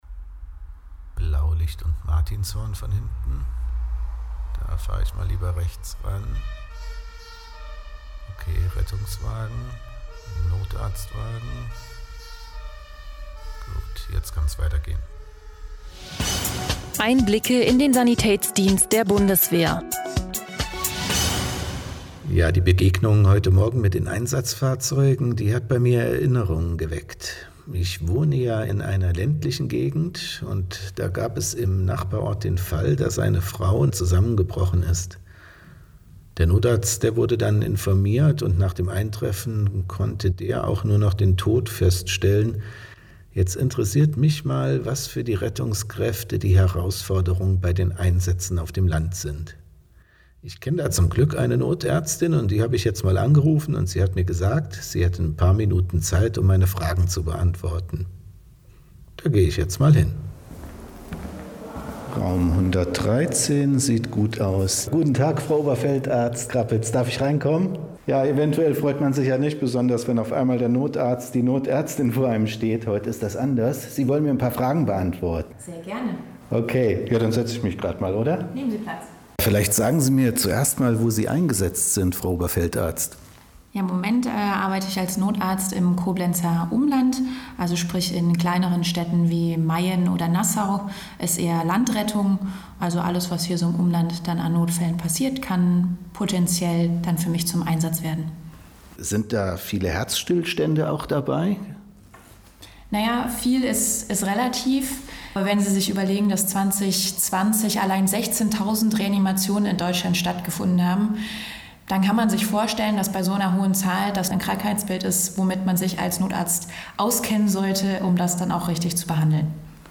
Audiointerview: Wiederbelebung auf dem Land
Audioreportage: Wiederbelebung auf dem Land